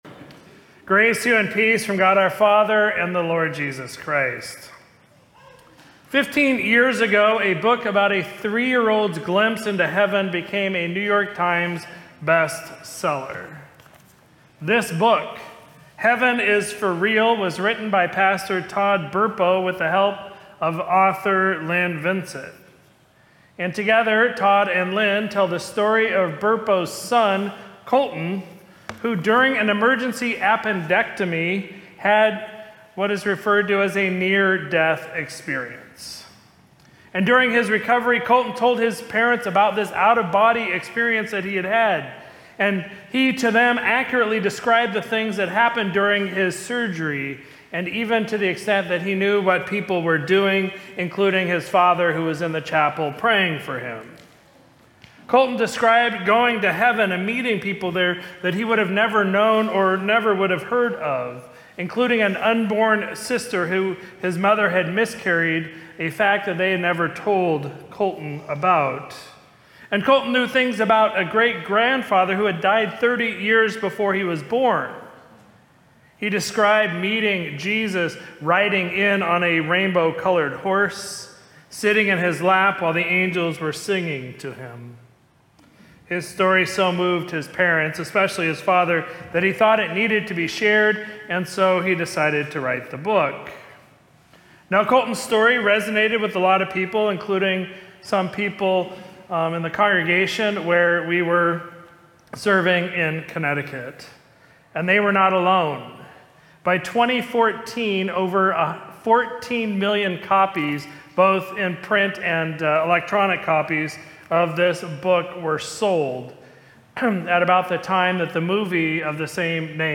Sermon from Sunday, November 9, 2025
Podcasts Giving Give Online 2025 Ministry Support Sierra Pacific Synod ELCA Living Lutheran Magazine Contact Us From Ascension Lutheran Church in Citrus Heights, California ...